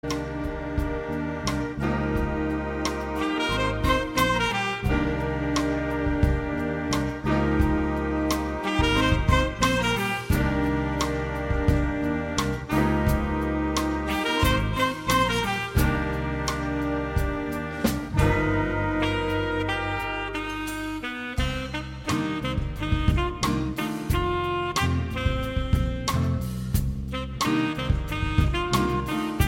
Hier finden Sie einige Hörproben aus unserem „20-Jahre Vincents Bigband“-Konzert, die aus lizenzrechtlichen Gründen nur eine Länge von 30 Sekunden haben.